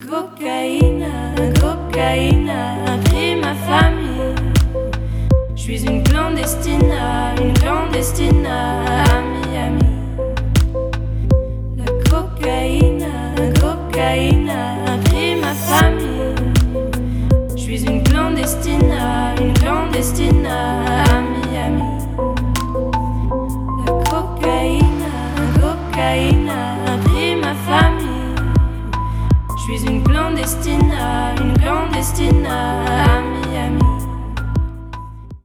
Ремикс
грустные # кавер